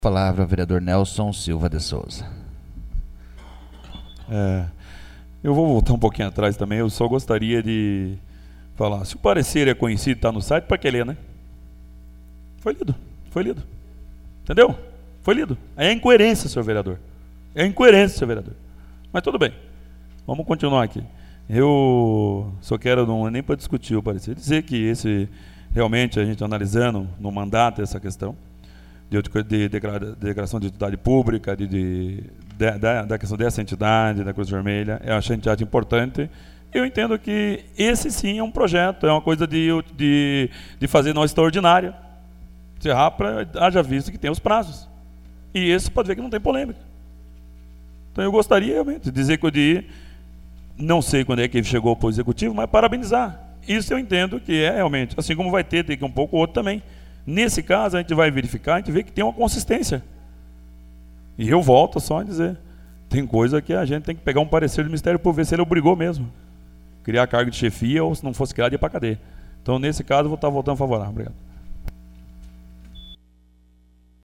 Discussão em 1º Turno.